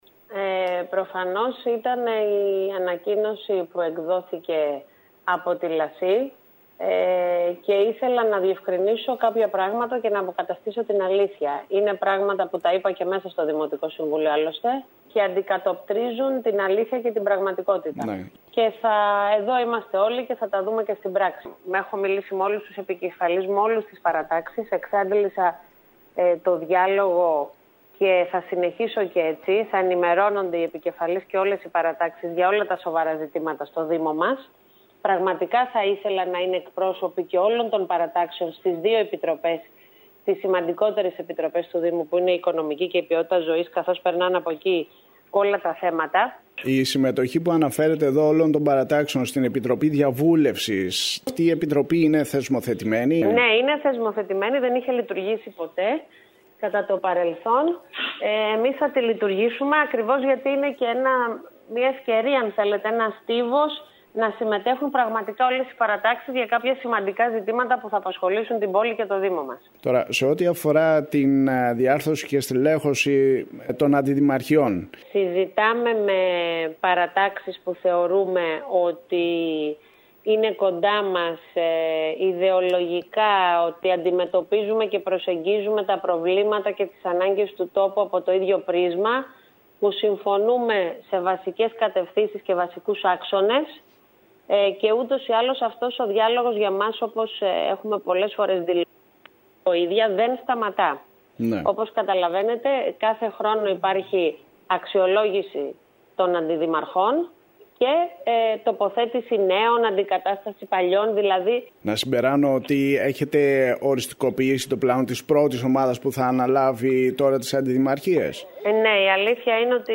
Τις πρώτες δηλώσεις της εφ όλης της ύλης έκανε σήμερα η Δήμαρχος Κεντρικής Κέρκυρας Μερόπη Υδραίου μιλώντας στην ΕΡΤ Κέρκυρας.